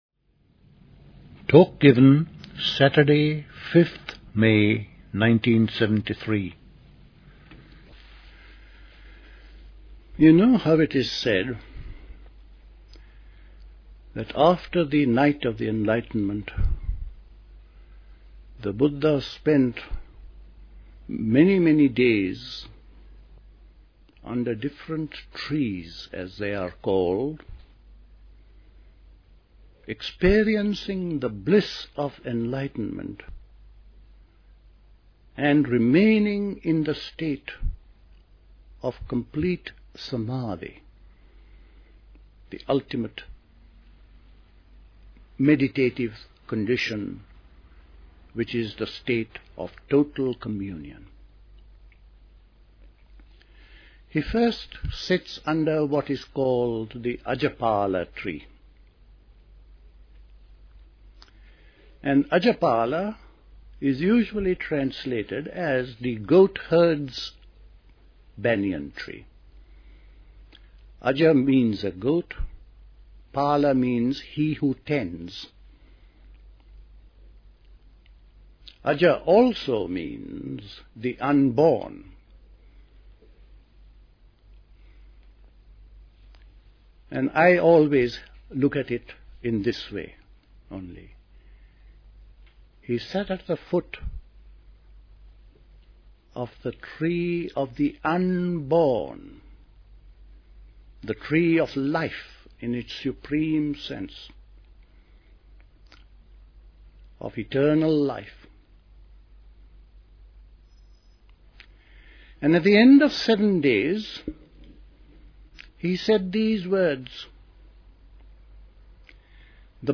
Dilkusha, Forest Hill, London